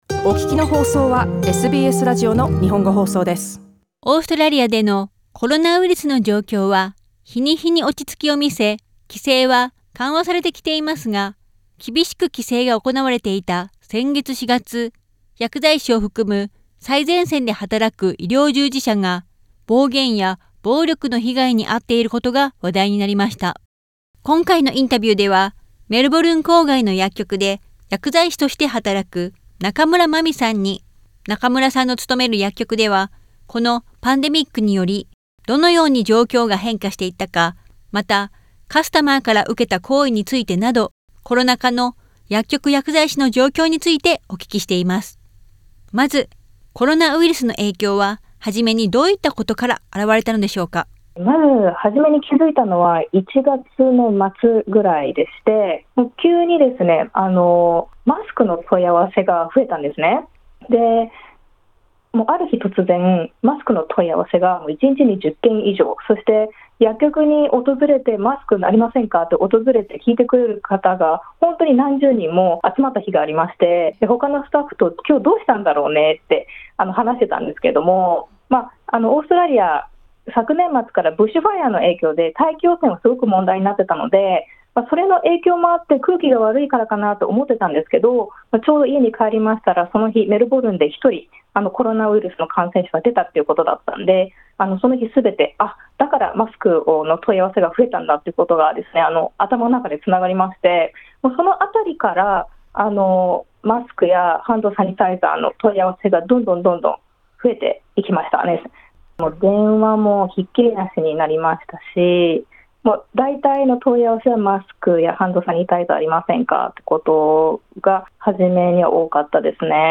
インタビュー：コロナ渦の薬局薬剤師の状況